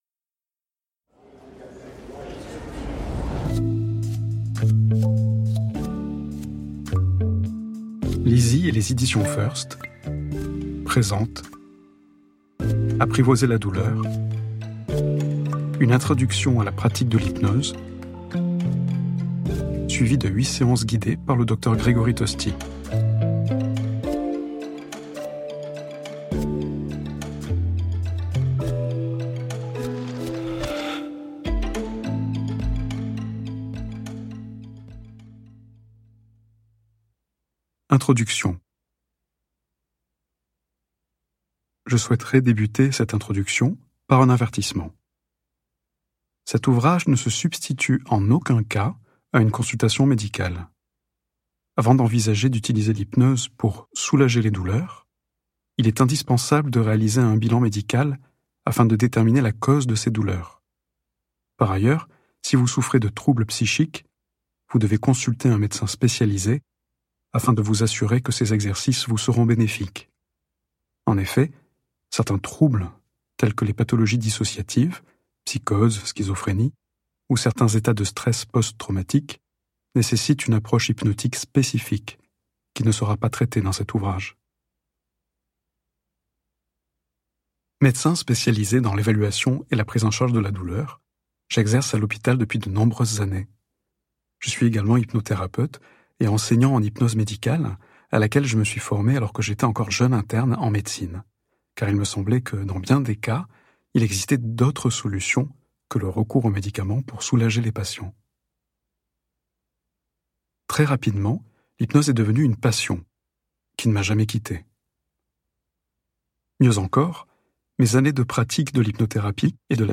8 séances d'audio hypnose
Get £1.95 by recommending this book 🛈 Installez-vous confortablement, laissez-vous guider par la voix d'un expert et prolongez votre écoute avec son livre compagnon ! Sou?rez-vous d'une douleur chronique ?